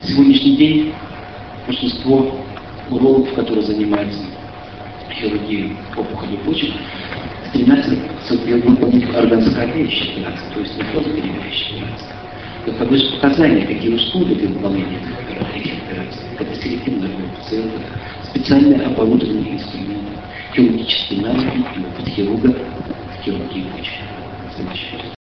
5 Российская Школа по эндоскопической и открытой урологии, 8-10 декабря 2004 года.
Лекция: ЛАПАРОСКОПИЯ В УРОЛОГИИ СЕГОДНЯ.